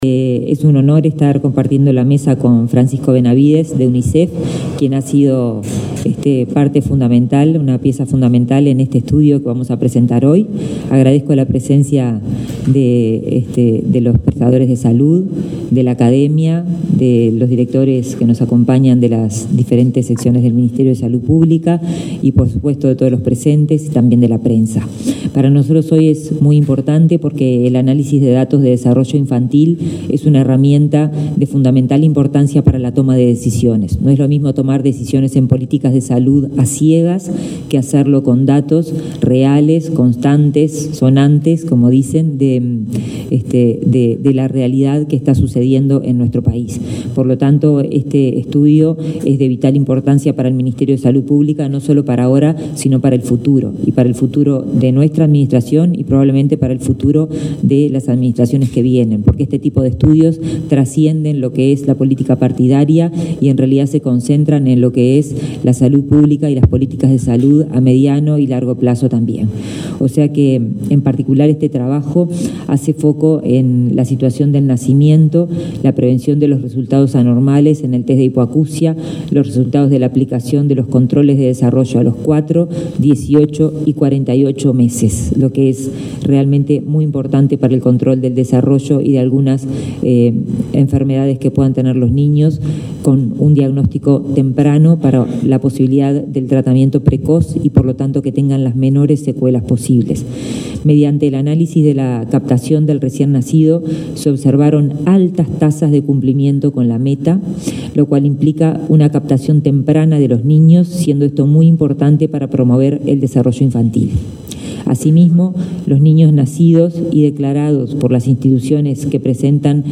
Palabras de la ministra de Salud Pública, Karina Rando
La ministra Karina Rando participó del evento y destacó los resultados.